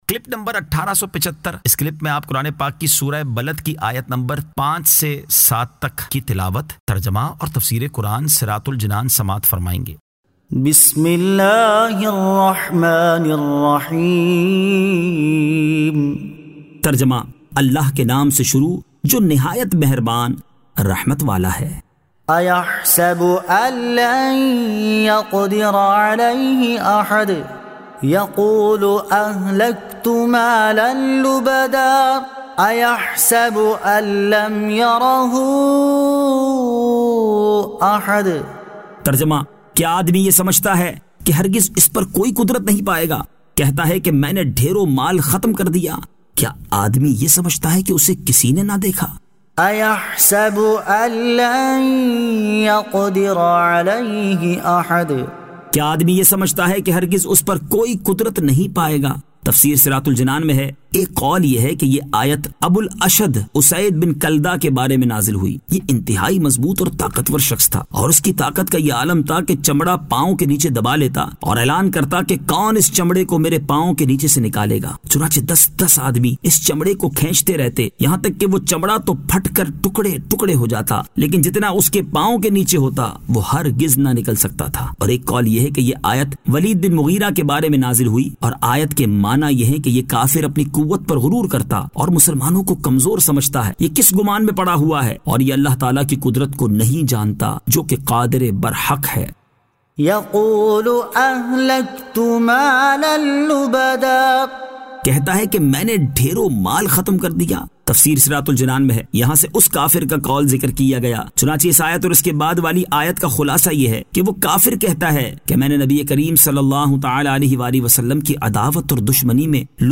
Surah Al-Balad 05 To 07 Tilawat , Tarjama , Tafseer